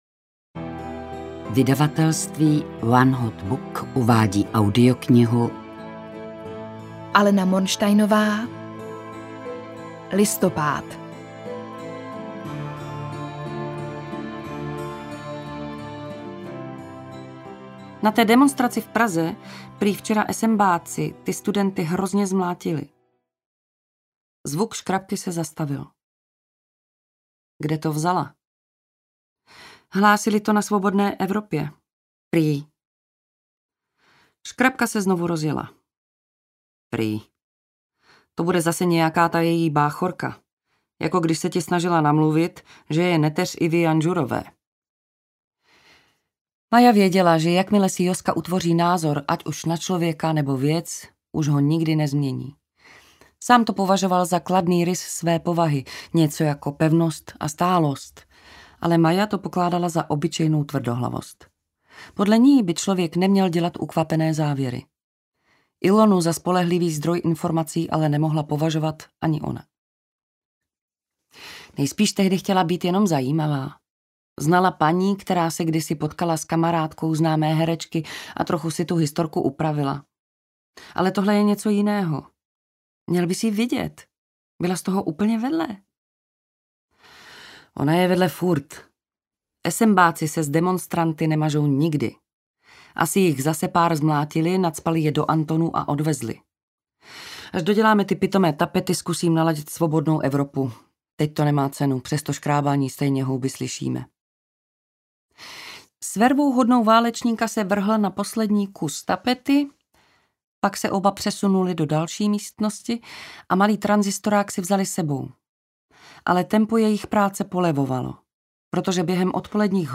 Listopád audiokniha
Ukázka z knihy